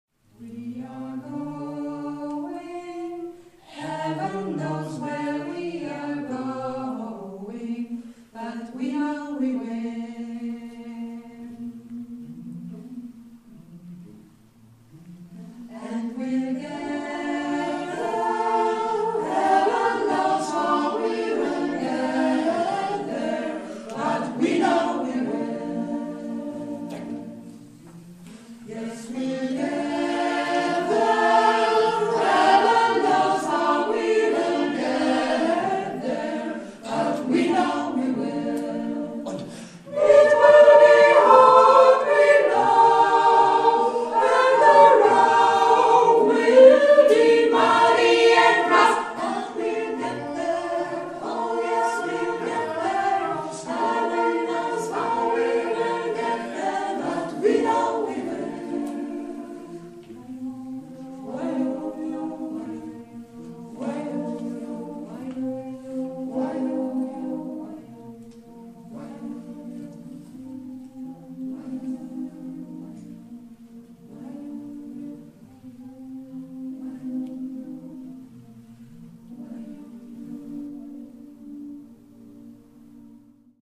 Ein paar Beispiele dafür, dass man auch mit einem kleinen Chor auf den Groove kommen kann.